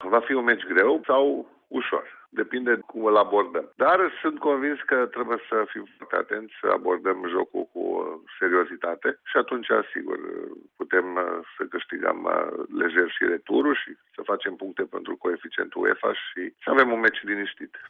Directorul de imagine al FC Steaua, Helmuth Duckadam, într-un interviu realizat în cadrul emisiunii ”Puzzle Sportiv”